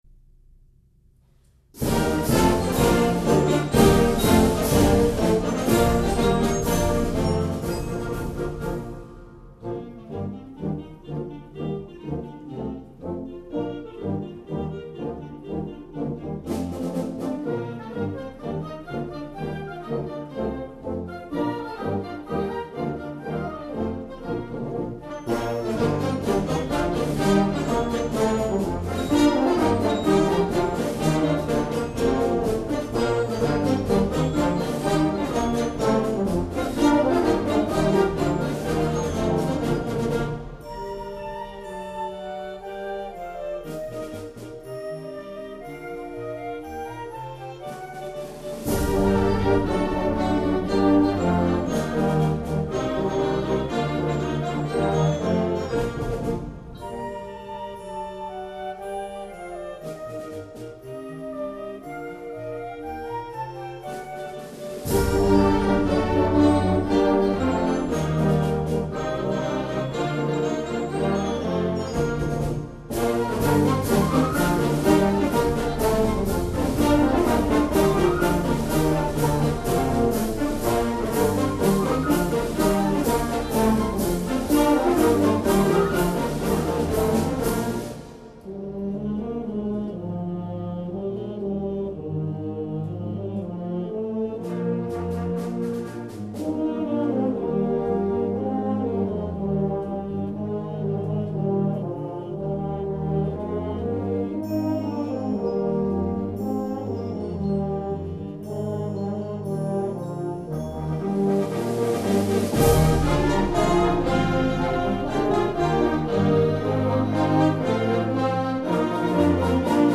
Standard Concert Band
Small bands should find this an ideal contest march.